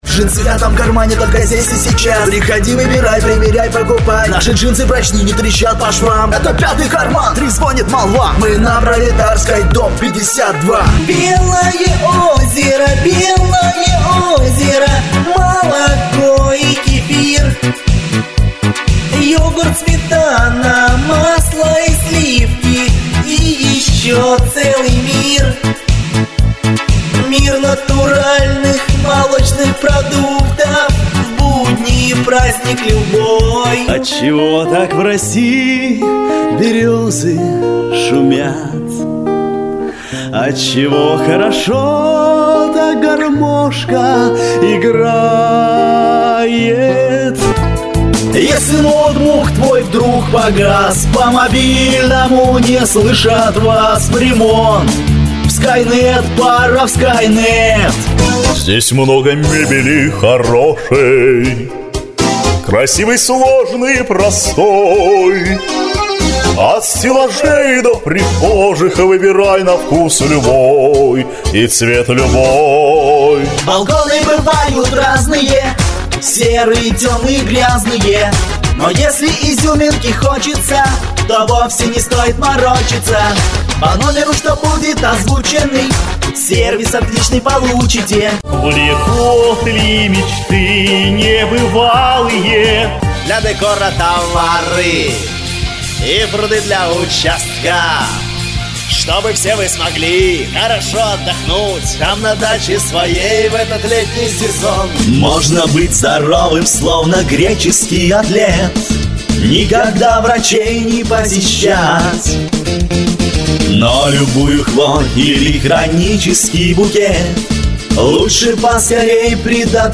Пародии очень оперативно, все возможности я сам не знаю, пишите какой персонаж нужен вам, буду пробовать.
Oktava-mk 105,Focusrite platinum.